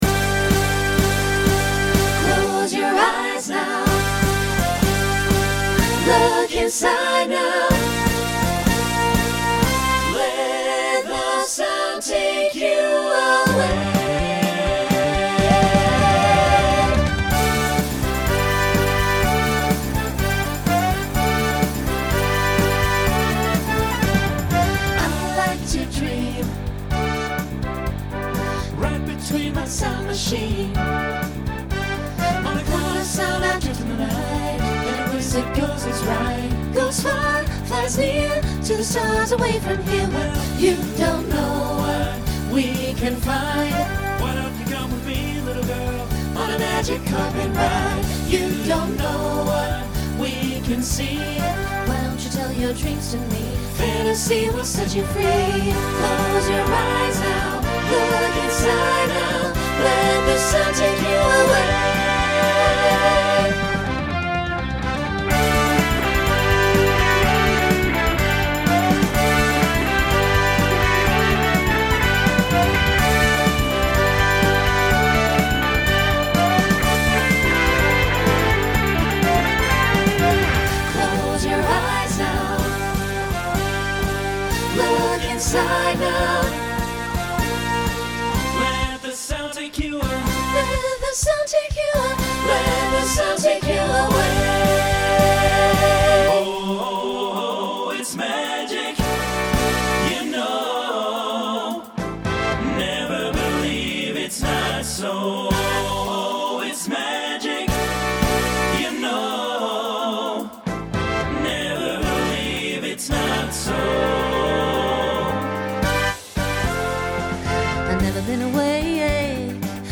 Voicing Mixed Instrumental combo Genre Rock